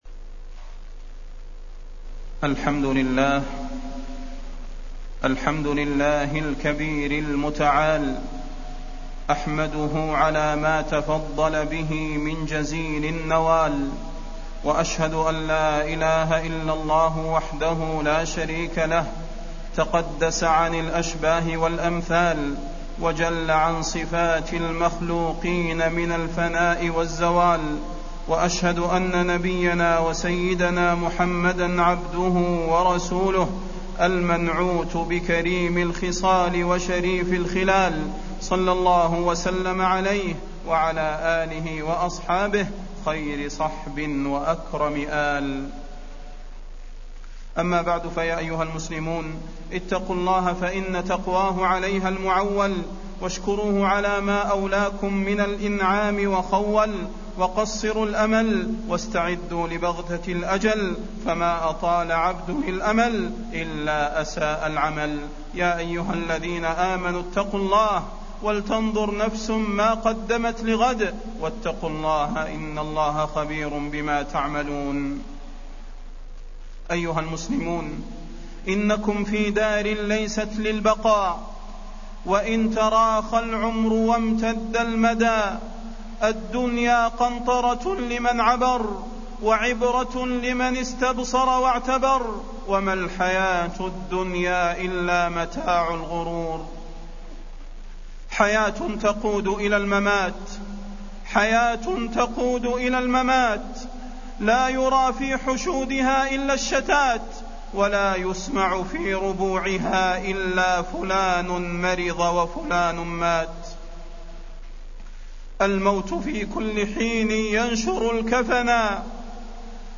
تاريخ النشر ٨ صفر ١٤٢٩ هـ المكان: المسجد النبوي الشيخ: فضيلة الشيخ د. صلاح بن محمد البدير فضيلة الشيخ د. صلاح بن محمد البدير فإنه ملاقيكم